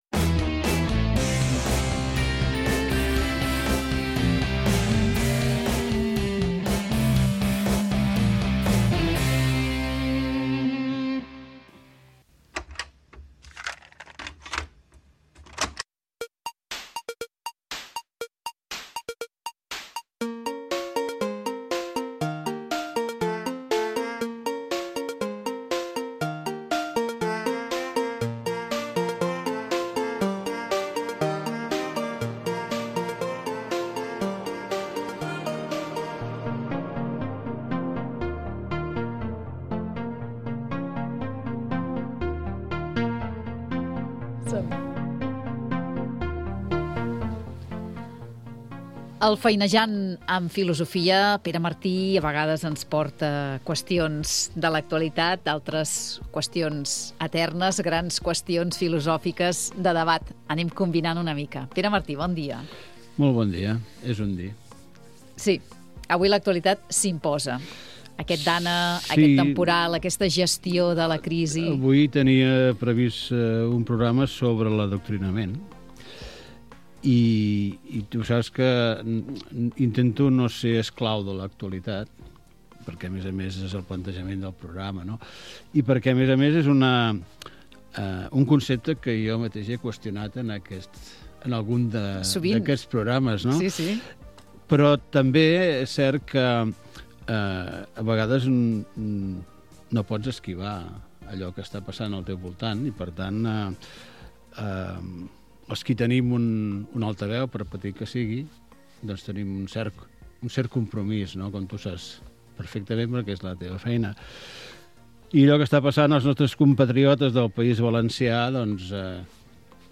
Magazín matinal